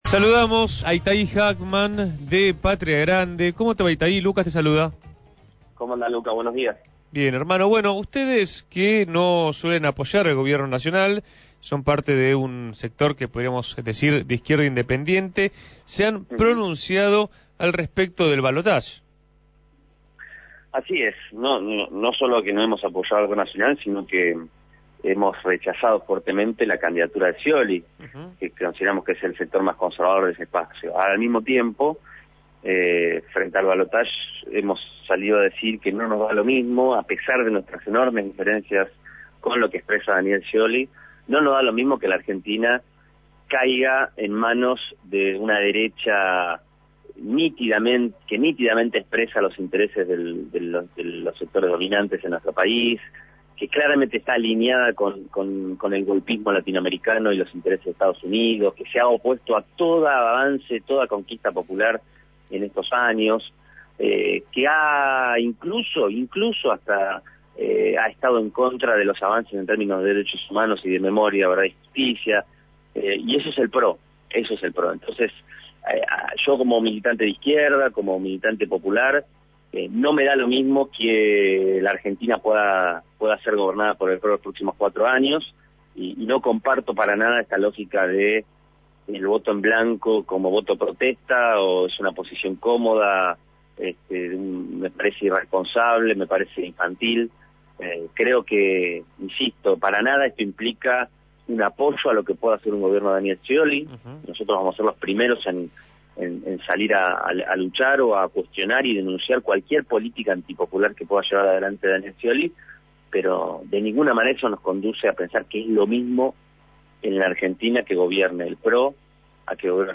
Itaí Hagman, referente del Movimiento Popular Patria Grande, dialogó en Punto de Partida sobre la situación electoral de cara al ballotage y manifestó que votar en blanco es una irresponsabilidad.